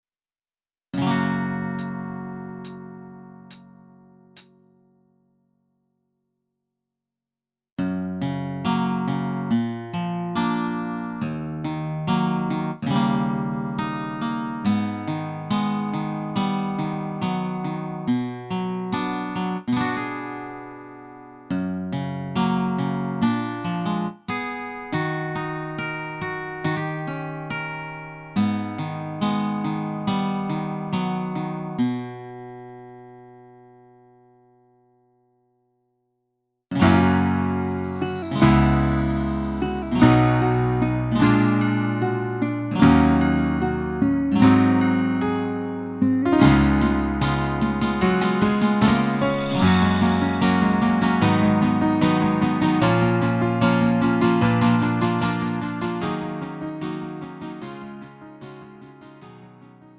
음정 -1키 3:45
장르 가요 구분 Lite MR